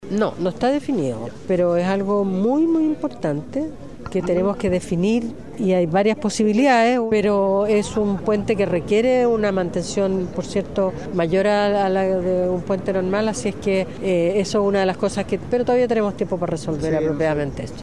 En su paso por Puerto Montt, y en conversación con Radio Bío Bío, la titular del MOP confirmó que aún no está definido el modelo de mantención que tendrá el Puente Chacao una vez que entre en funcionamiento, pese a que la obra avanza y su entrega está proyectada para 2028.